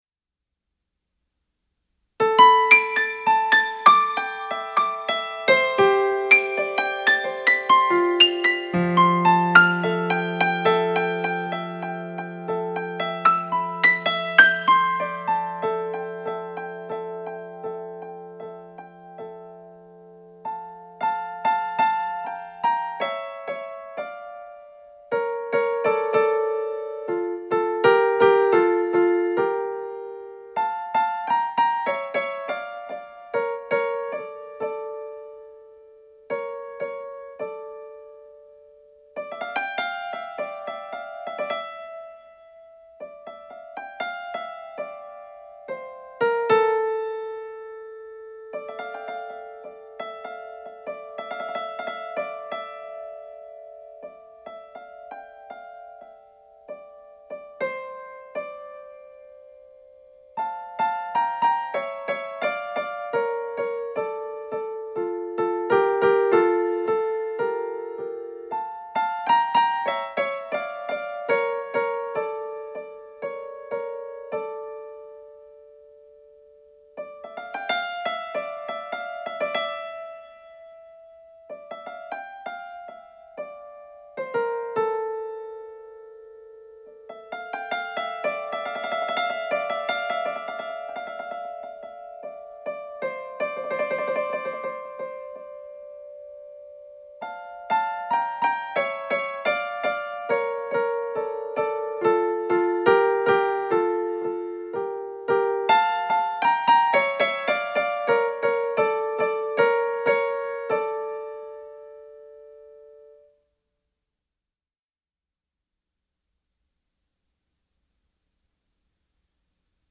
Thème Comme un Signal,  piano seul,  durée 1m50,   Version presqu’achevée